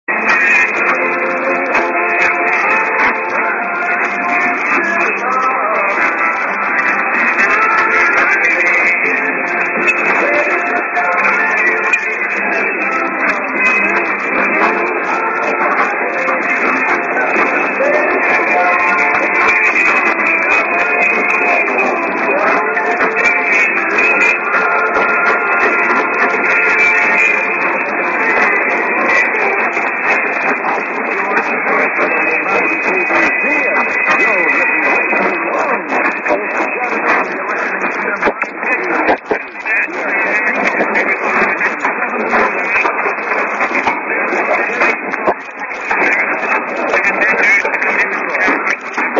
->ID:･･･KBC･･･(man)->　Heavy QRM+Side
当家の受信した音声をＵＰしますが、サイドが凄いので聞きにくいですが、心してお聞き下さい。
３０秒過ぎに、フルＩＤがどの様に出るのかは判りませんが・・・「ＫＢＣ」という単語は聞こえました。